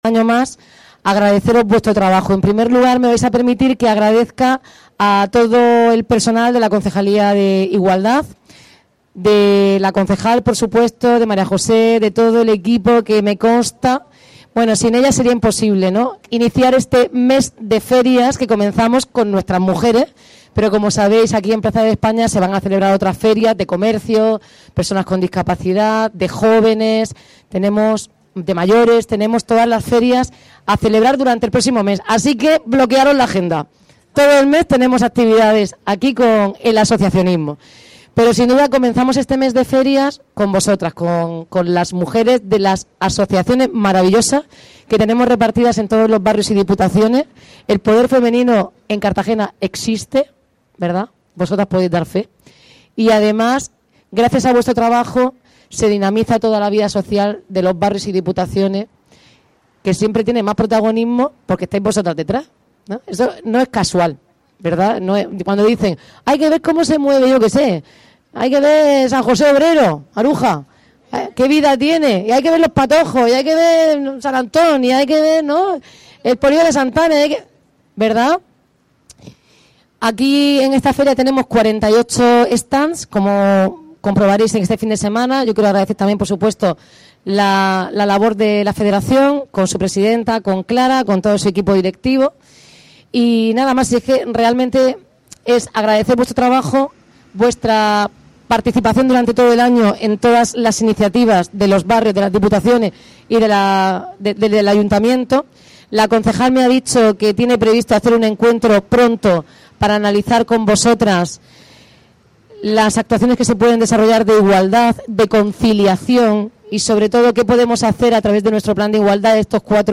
Enlace a Declaraciones de la alcaldesa, Noelia Arroyo